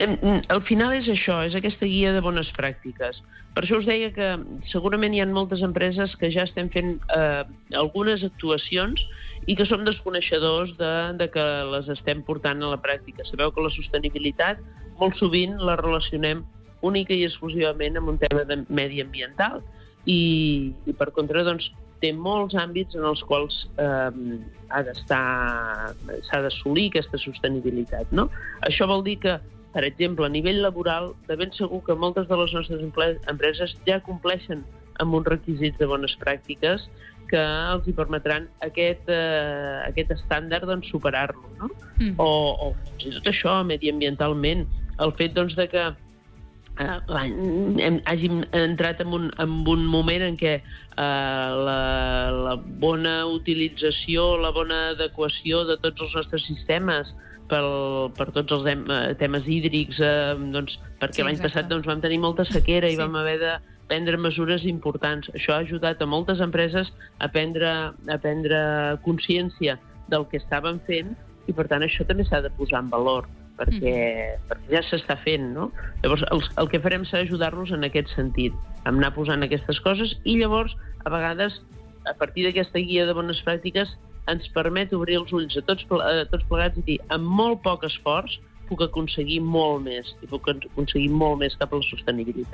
Supermatí - entrevistes